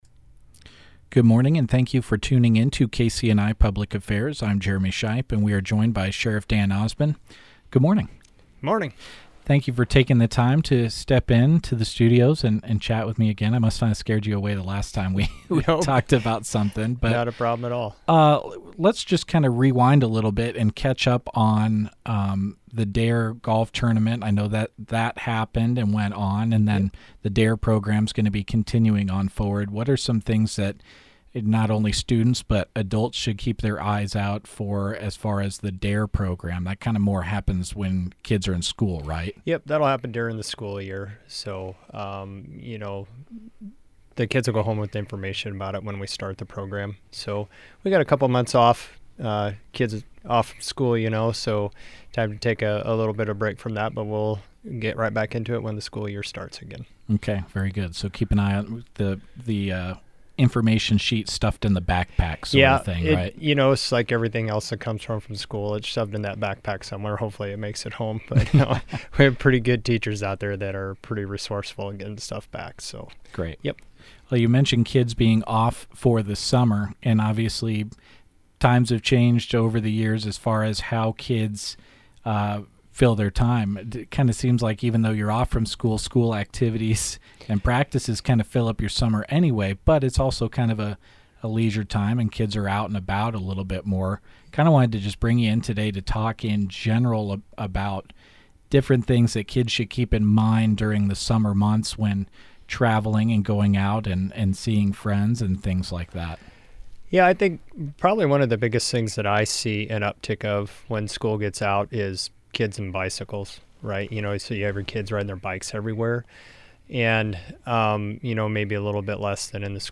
CUSTER COUNTY, NE – Custer County Sheriff Dan Osmond spoke with KCNI/KBBN News on Wednesday about current public concerns, how the public can help, and general summer safety tips.